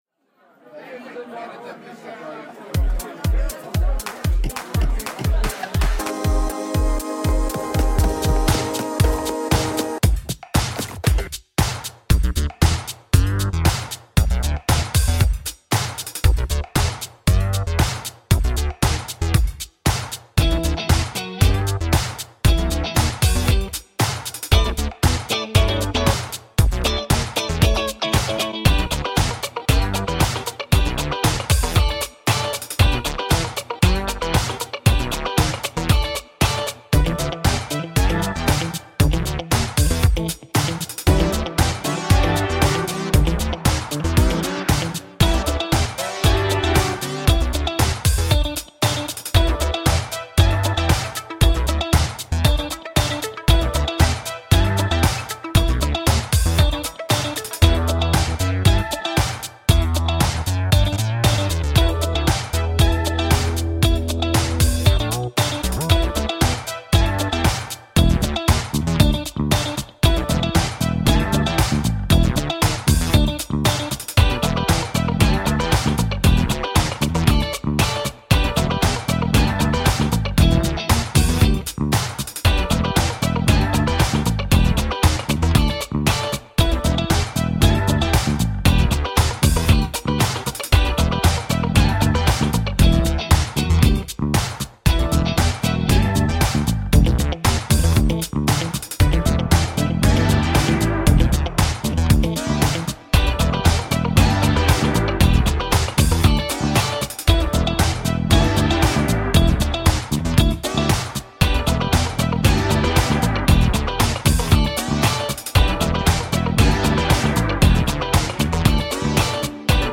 Skapade lite dansmusik i vinterfredagsnattskvällen. Dryga tre minuter smack-funk.